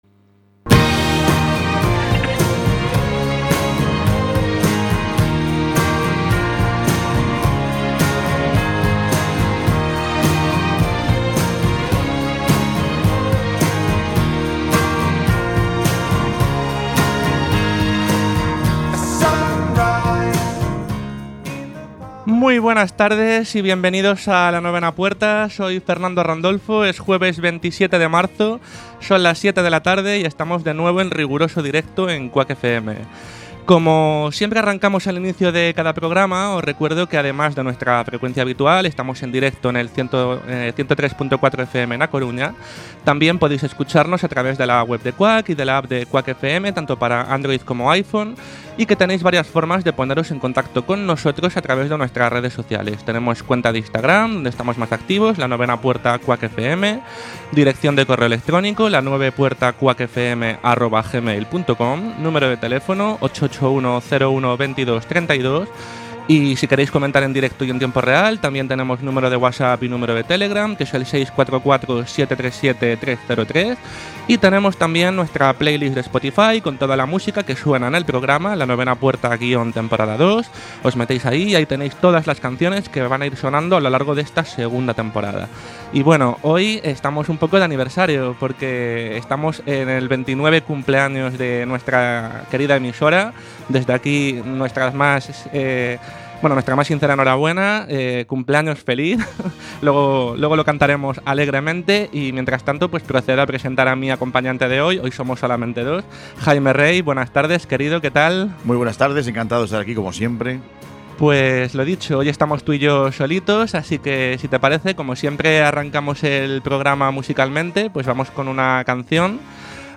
Programa de opinión y actualidad en el que se tratan temas diversos para debatir entre los colaboradores, con algún invitado relacionado con alguno de los temas a tratar en el programa y que además cuenta con una agenda de planes de ocio en la ciudad y alguna recomendación musical y de cine/series/libros.